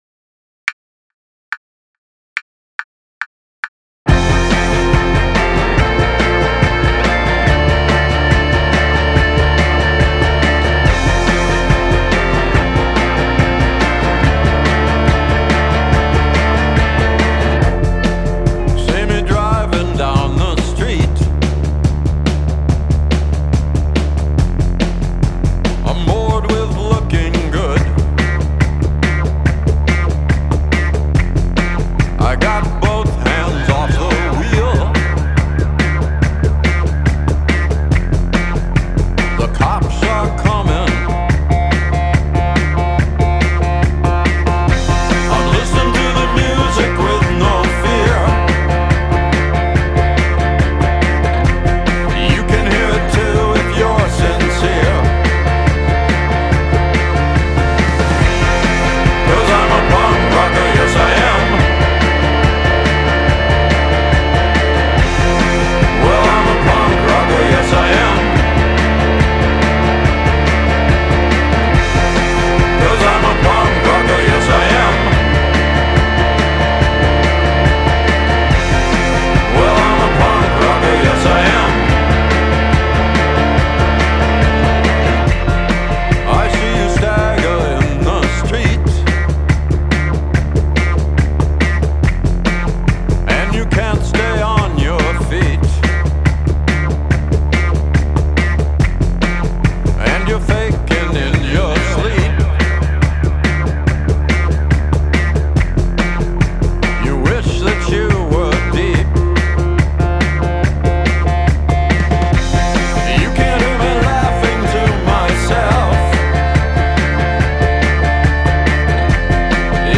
Drumless